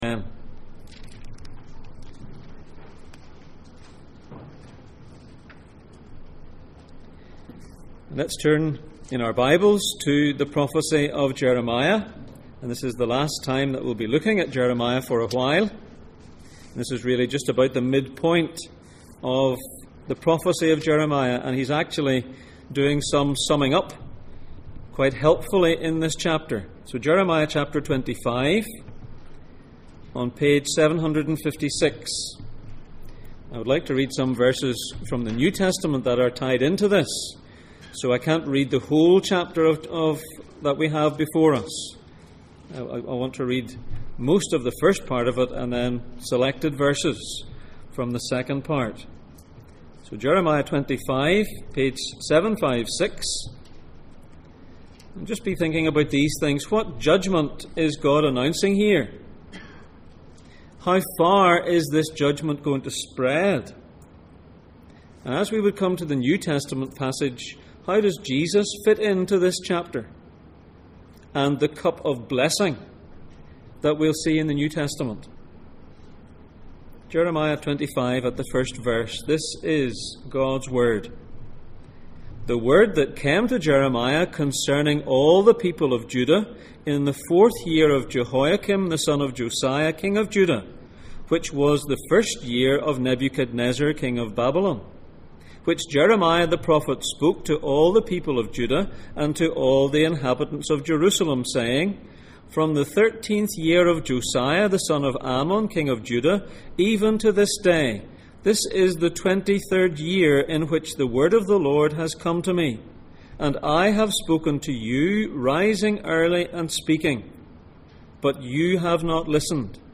The highs and lows of Jeremiah Passage: Jeremiah 25:1-38, Matthew 26:26-42 Service Type: Sunday Morning